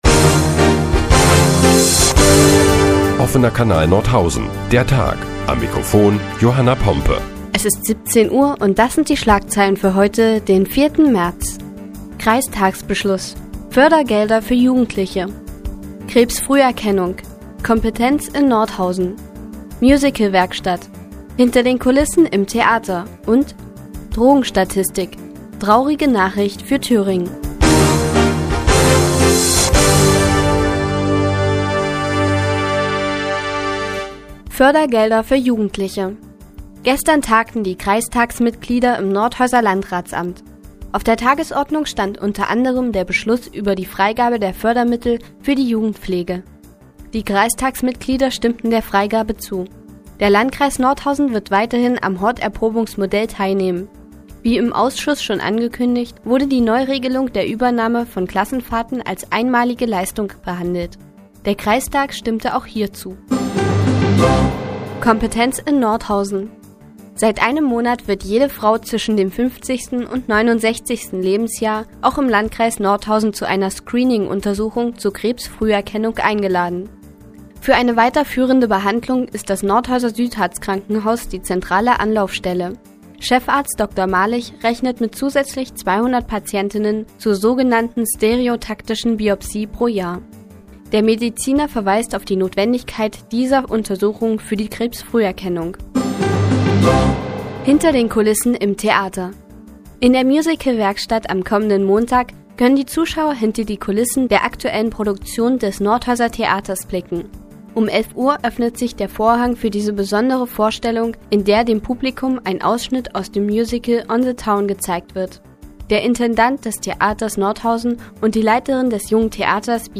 Die tägliche Nachrichtensendung des OKN ist nun auch in der nnz zu hören. Heute geht es unter anderem um die gestrigen Kreistagsbeschlüsse und die traurige Drogenstatistik für Thüringen.